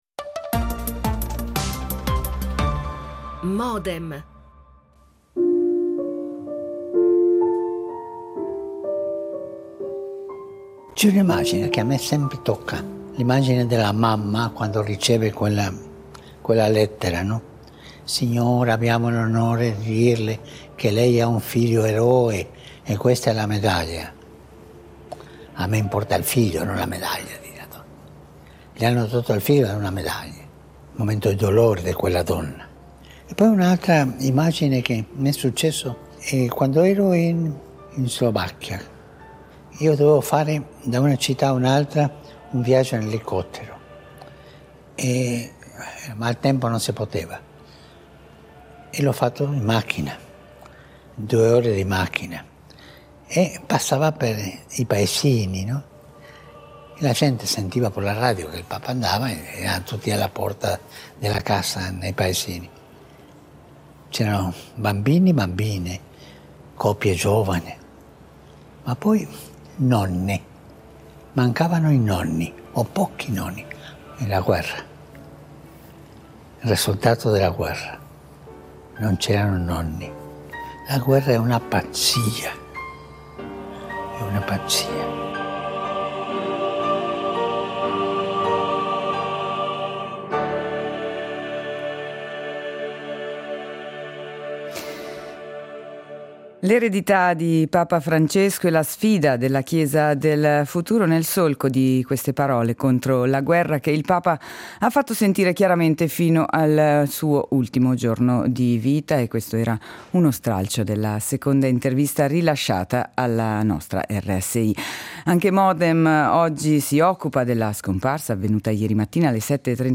Modem, appuntamento quotidiano (dal lunedì al venerdì) in onda dal 2000, dedicato ai principali temi d’attualità, che vengono analizzati, approfonditi e contestualizzati principalmente attraverso l’apporto ed il confronto di ospiti in diretta.